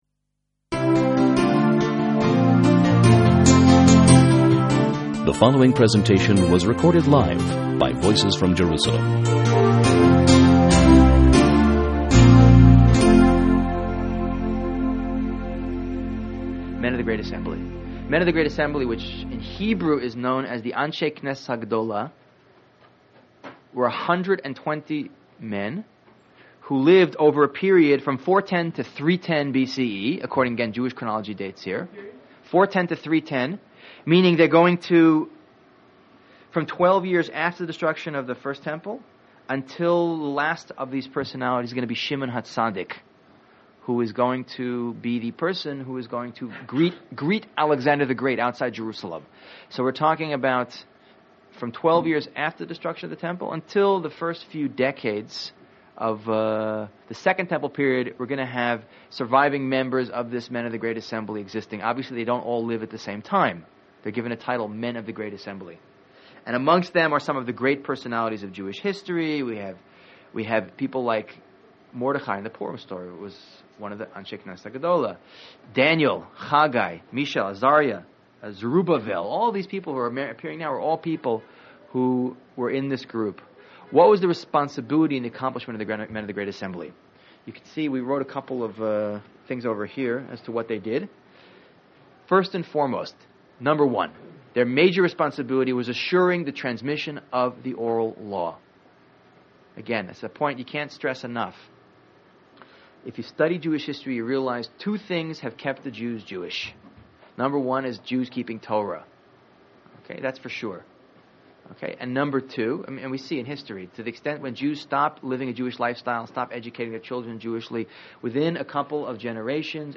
Contribute: Add your Summary, Outline or Thoughts on this Lecture Commenting is not available in this channel entry.